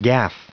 Prononciation du mot gaff en anglais (fichier audio)
Prononciation du mot : gaff